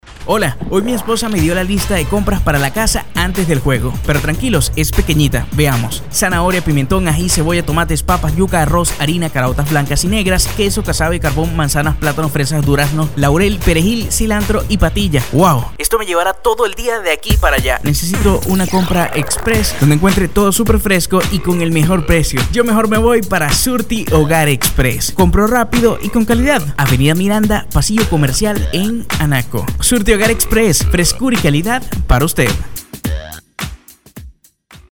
VOZ ADAPTABLE A CUALQUIER NECESIDAD INFLEXIONES MANEJO DE LA INTENCIÓN NECESARIA PROYECCIÓN DICCIÓN
spanisch Südamerika
Sprechprobe: Werbung (Muttersprache):